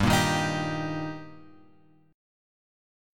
Gm#5 chord {3 1 1 x 4 3} chord